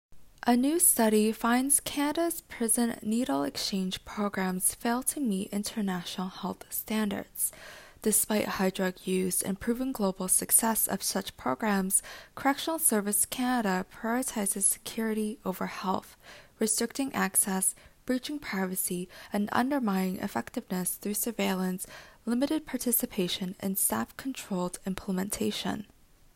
Audio summary